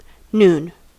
Ääntäminen
IPA : /ˈnuːn/